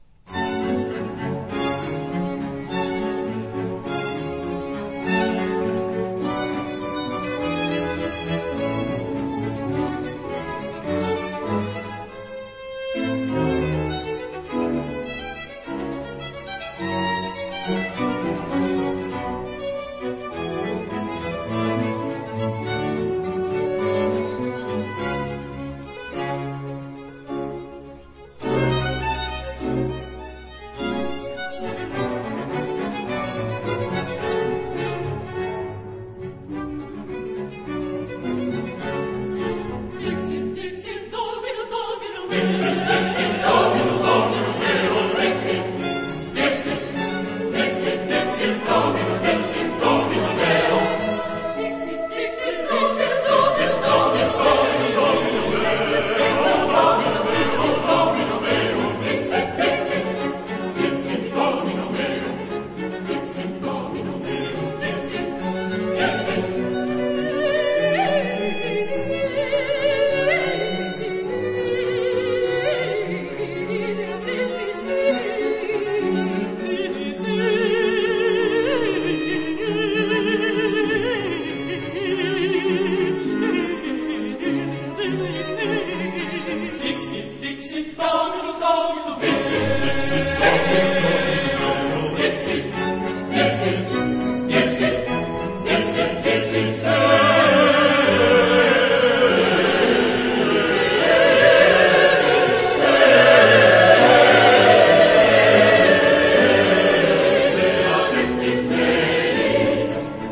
這是一份現場錄音，小型的合奏團，加上18人的小合唱團組成。
但是，現場的演出，非常動人。